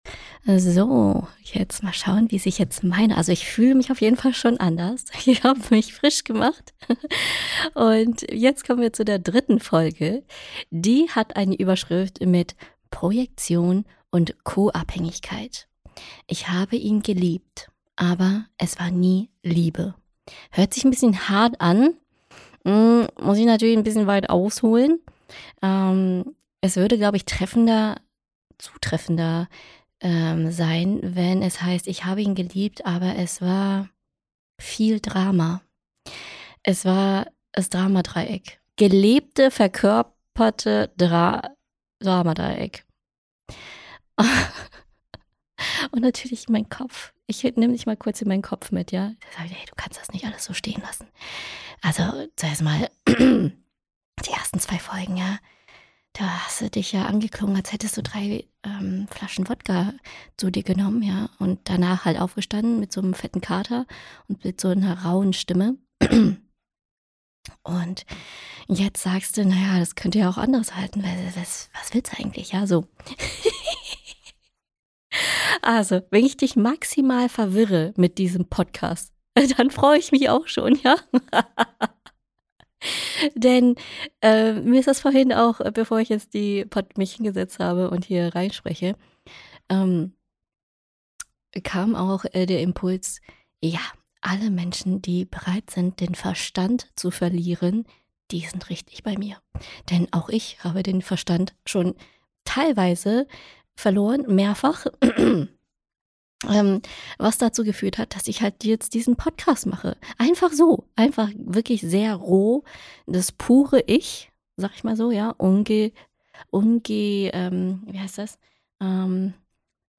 Vorlesung aus dem eigenen Buch